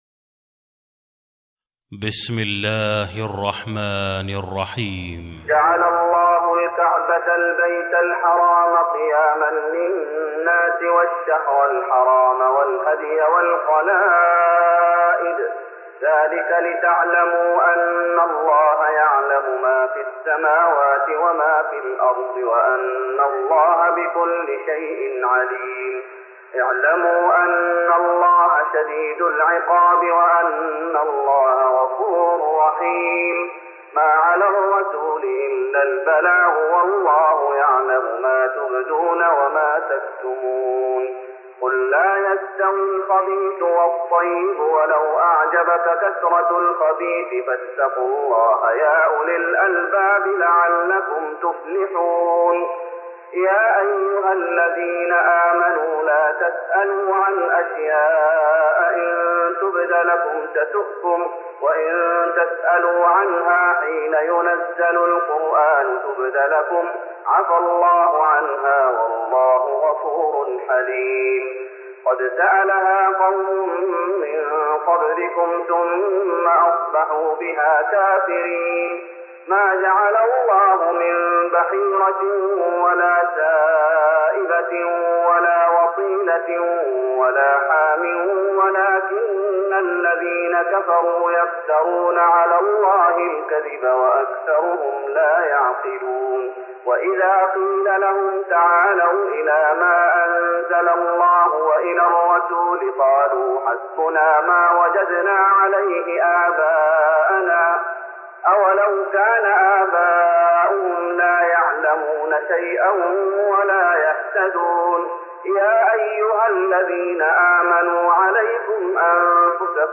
تراويح رمضان 1414هـ من سورة المائدة (97-120) Taraweeh Ramadan 1414H from Surah AlMa'idah > تراويح الشيخ محمد أيوب بالنبوي 1414 🕌 > التراويح - تلاوات الحرمين